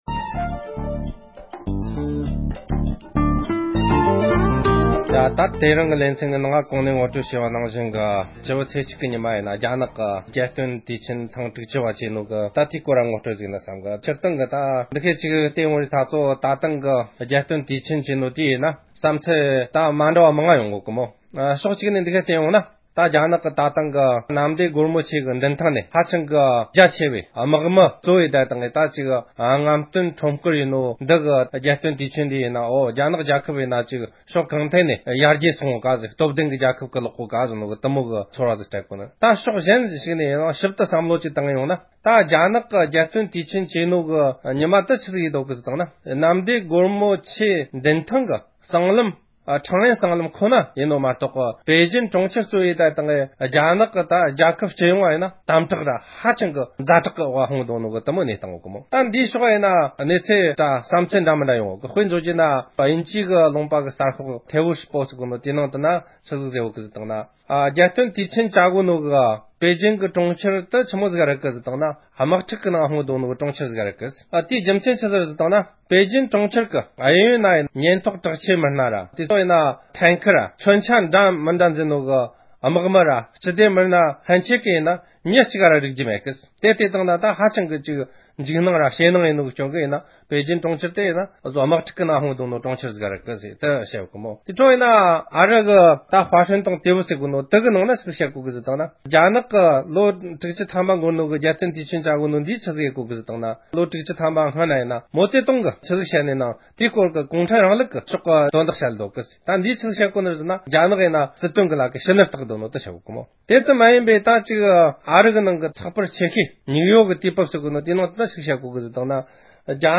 རྒྱ་ནག་མི་དམངས་སྤྱི་མཐུན་རྒྱལ་ཁབ་དབུ་བརྙེས་ནས་ལོ་ངོ་དྲུག་ཅུ་འཁོར་བའི་དུས་དྲན་སྲུང་བརྩིའི་མཛད་སྒོ་དང་འབྲེལ་བའི་དཔྱད་གཏམ།